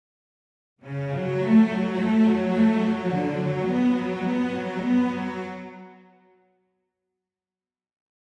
Сравнение струнных библиотек - легато
Вложения VSL Dimension Leg v2.mp3 VSL Dimension Leg v2.mp3 295,3 KB · Просмотры: 1.078 VSL Dimension v2 full divisi.mp3 VSL Dimension v2 full divisi.mp3 327,2 KB · Просмотры: 1.045